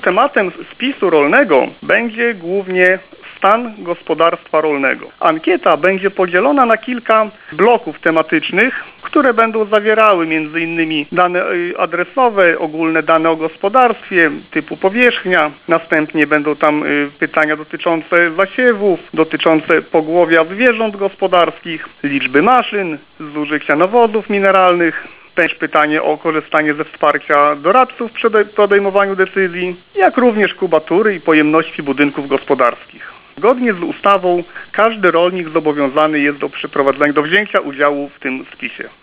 O szczegółach Krzysztof Gwaj, zastępca wójta gminy Suwałki.